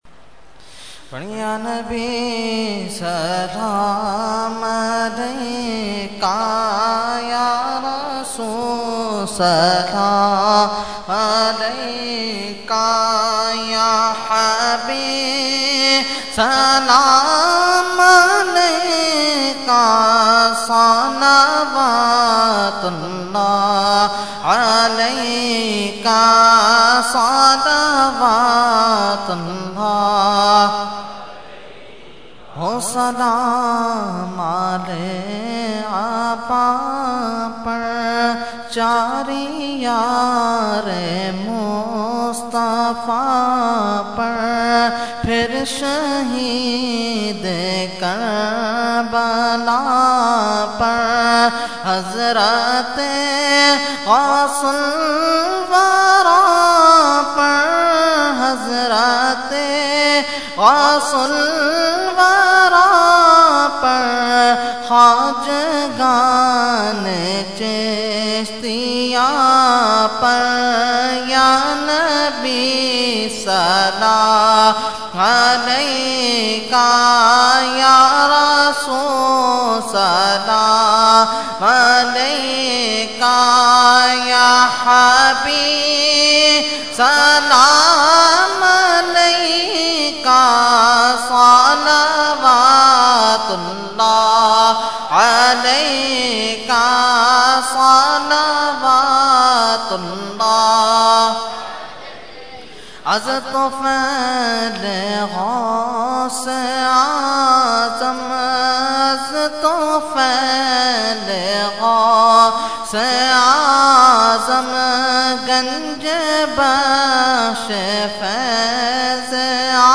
Category : Salam | Language : UrduEvent : Mehfil Ramzan Noor Masjid 5 August 2012